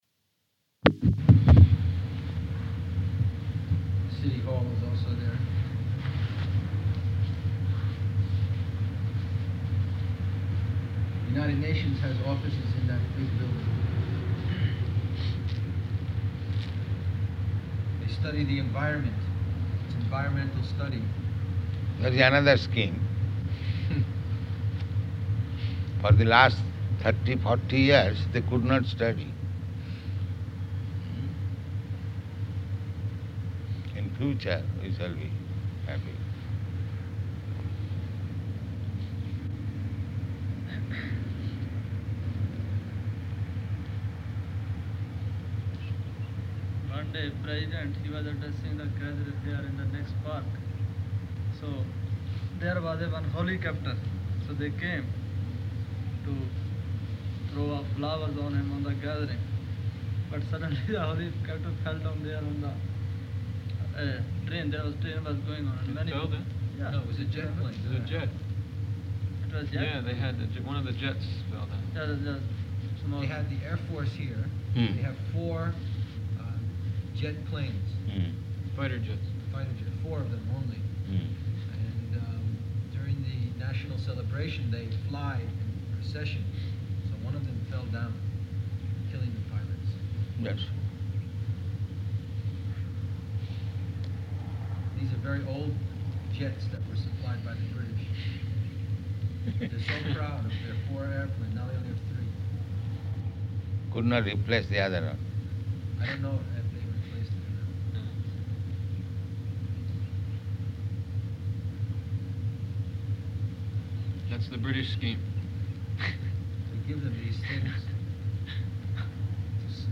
Room Conversation
-- Type: Conversation Dated: October 29th 1975 Location: Nairobi Audio file